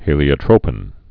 (hēlē-ə-trōpĭn, -ŏtrə-)